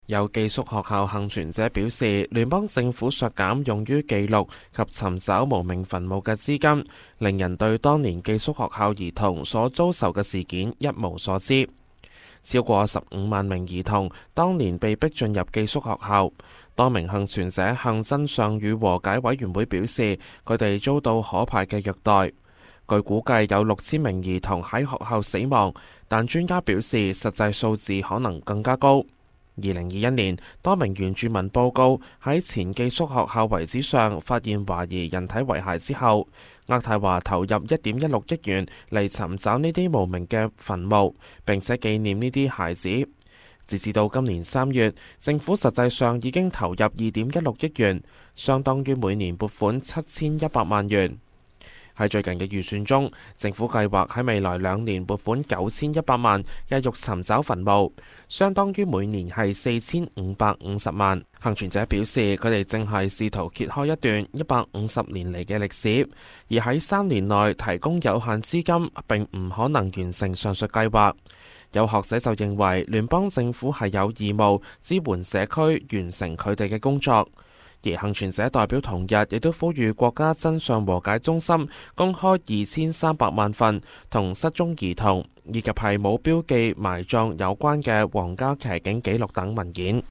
news_clip_20748.mp3